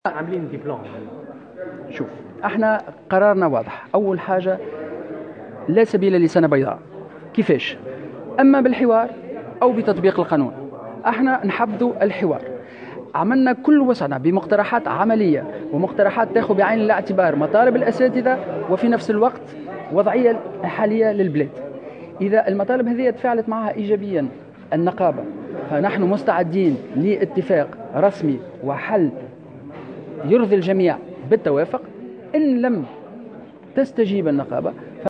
واعتبر المجلس الوزاري مطالب الأساتذة المحتجين تعجيزية، مقرا باستيفاء كل المساعي المبذولة من طرف وزارة التعليم العالي و البحث العلمي للتوصل إلى حل توافقي، بحسب ما نقلته مراسلة "الجوهرة اف أم".وفي سياق متصل، أكد وزير التعليم العالي، سليم خلبوس، قائلا في تصريحات صحفية بأنه: " لا سبيل لسنة بيضاء.. إما بالحوار أو بتطبيق القانون"، بحسب تعبيره.